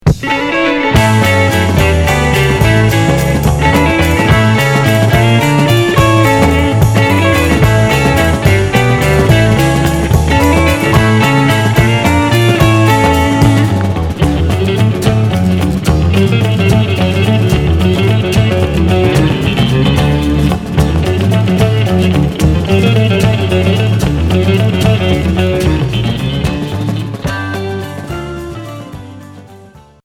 Pop progressif Deuxième 45t retour à l'accueil